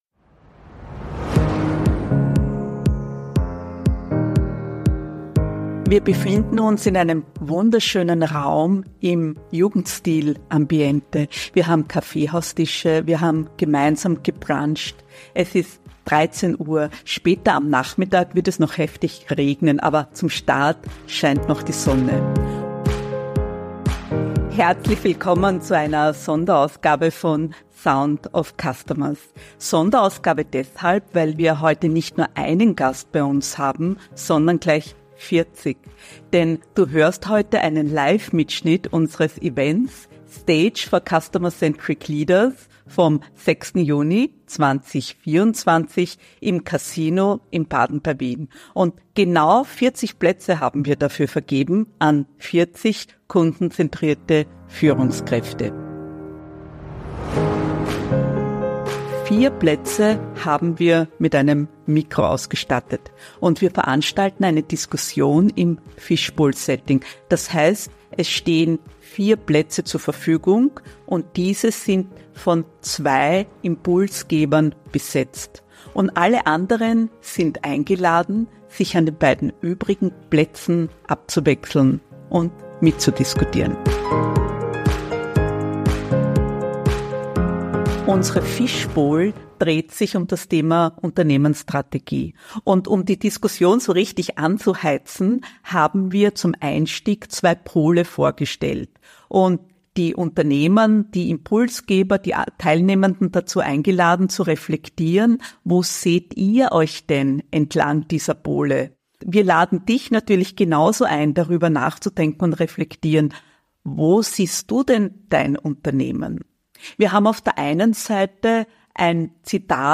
Kundenzentrierte Leader im Fishbowl 1 (Live-Mitschnitt): Customer Experience in der Unternehmensstrategie ~ Sound of Customers Podcast
Herzlich willkommen zur exklusiven Fishbowl-Diskussion im Casino in Baden bei Wien!